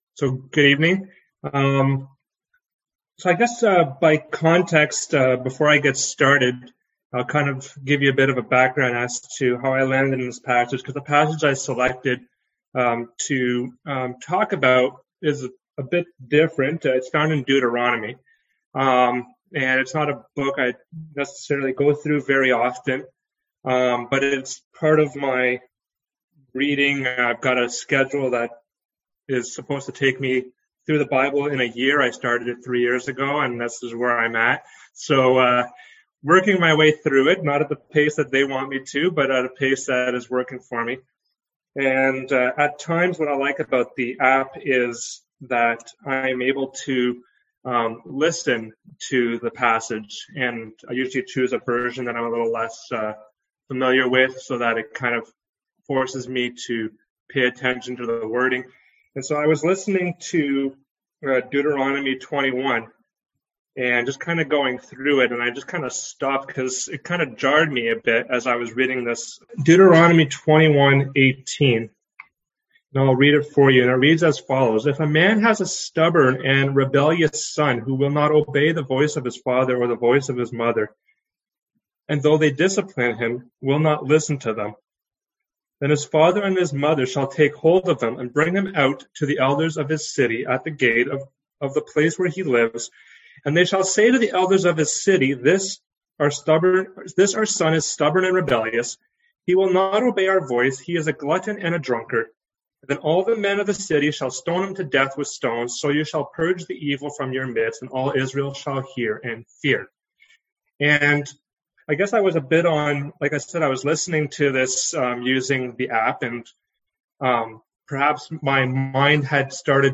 Service Type: Seminar Topics: Discipline